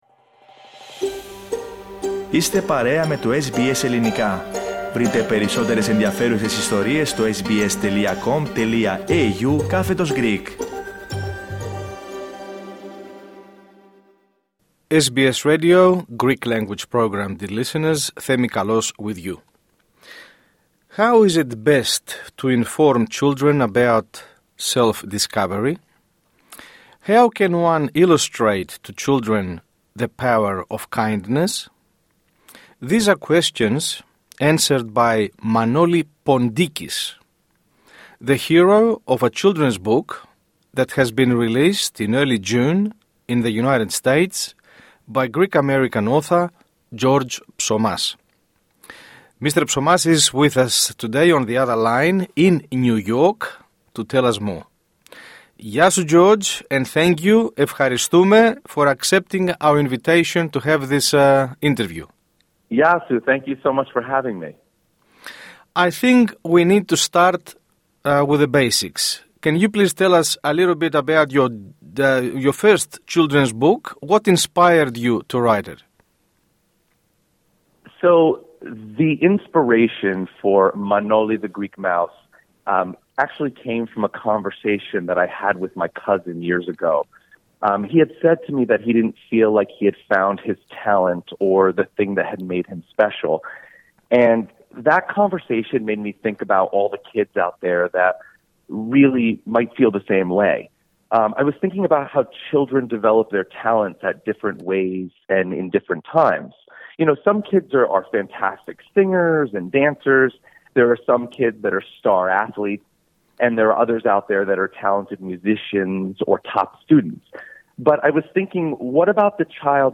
SBS Greek View Podcast Series